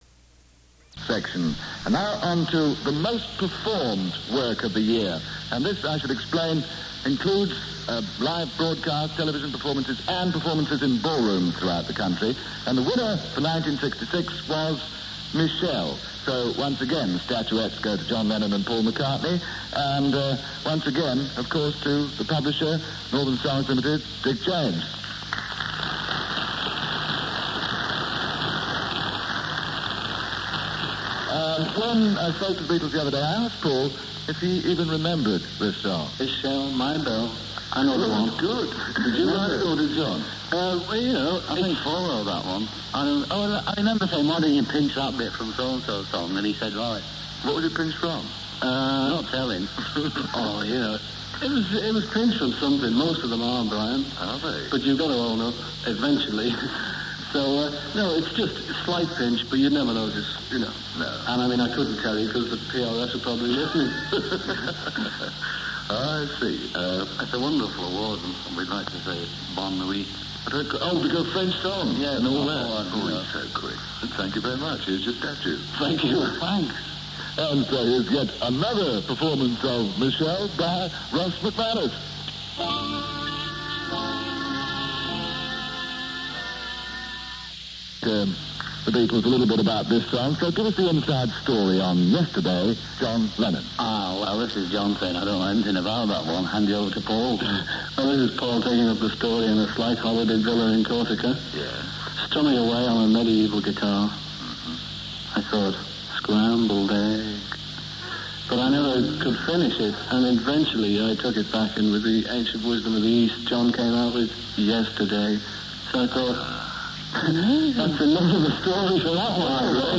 The BBC's Brian Matthew interviewed
These interviews were recorded on reel E63372.
Studio Two 7.00pm-3.00am